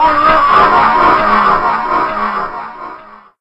scrump_die.ogg